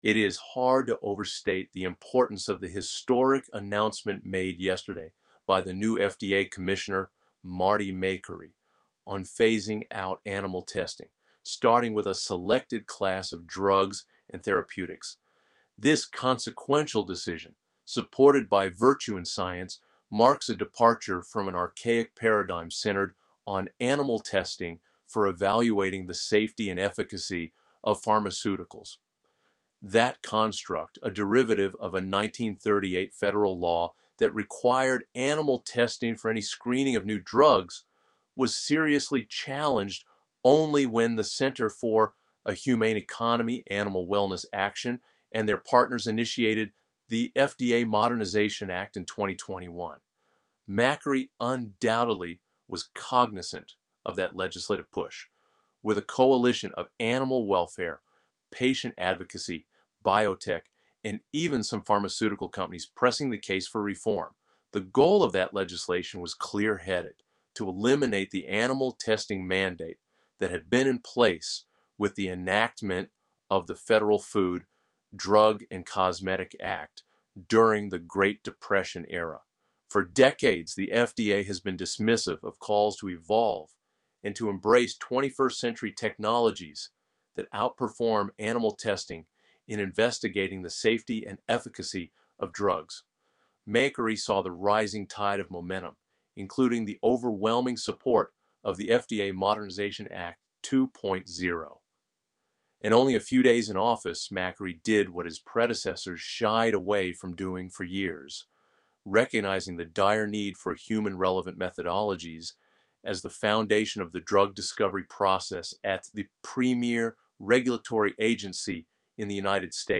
FDA Commissioner initiated off ramping of animal testing at the agency to improve human health, spare animals, and bolster a humane economy You can listen to an AI-generated reading of this story here: